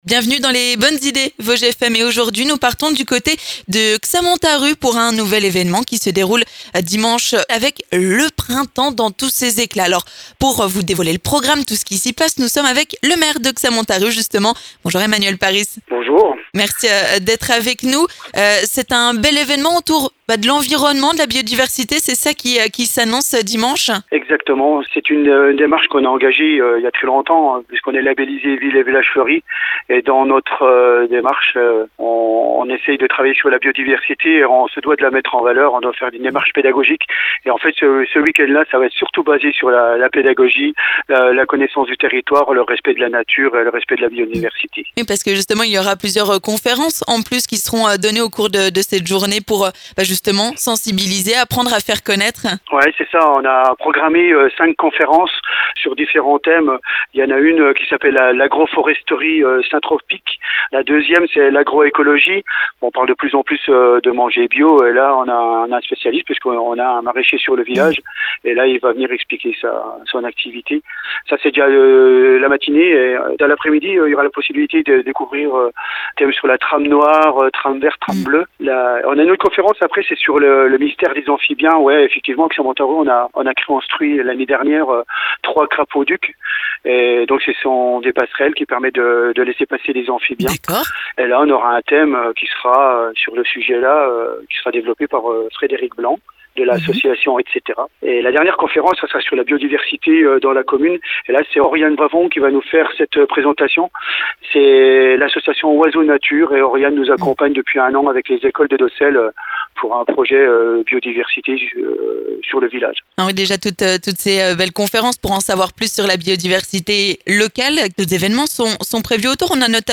On vous dévoile le programme dans les Bonnes Idées Vosges FM avec le maire de Xamontarupt, Emmanuel Parisse.